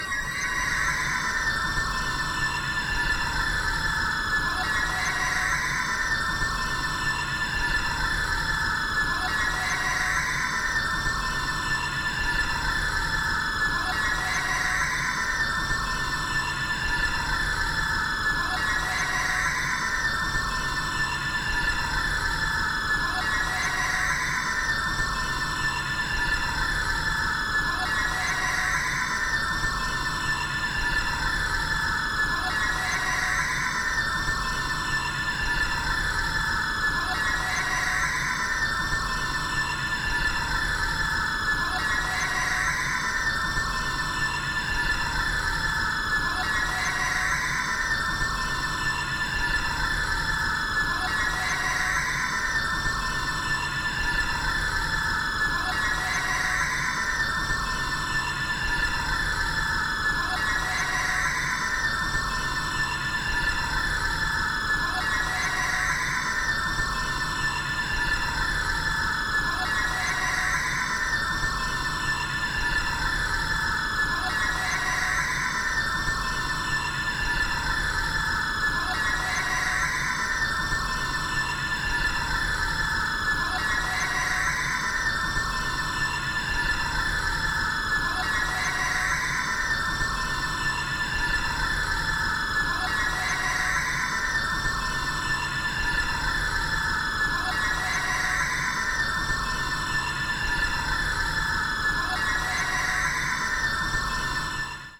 どことなく神秘的な佇まいも◎！曲によってはアンビエントに近い感触もあり、なんとなく“鎮守の森”という言葉が浮かびました！